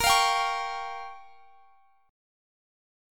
Bbm6 Chord
Listen to Bbm6 strummed